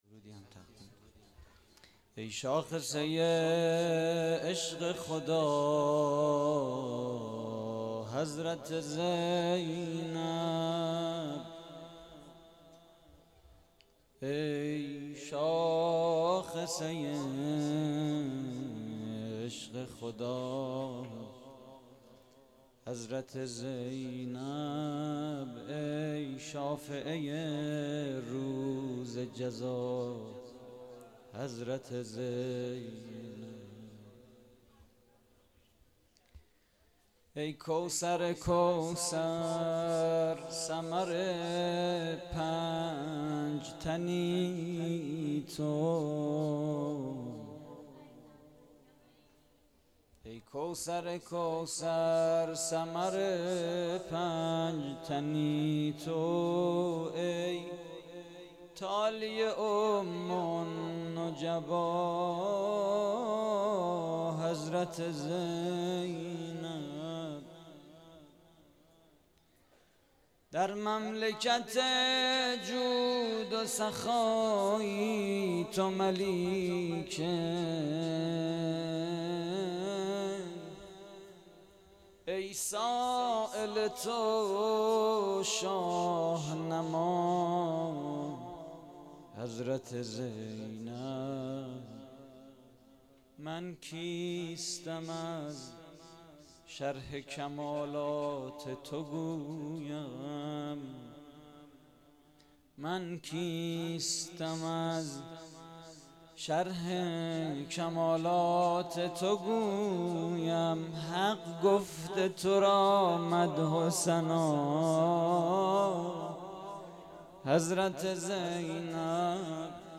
مدح: ای شاخصه‌ی عشق خدا
مراسم جشن ولادت حضرت زینب (س)